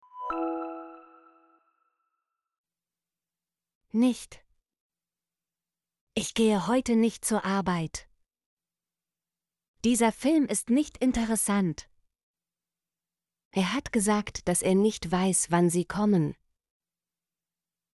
nicht - Example Sentences & Pronunciation, German Frequency List